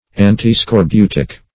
Search Result for " antiscorbutic" : The Collaborative International Dictionary of English v.0.48: Antiscorbutic \An`ti*scor*bu"tic\, a. (Med.) Counteracting scurvy.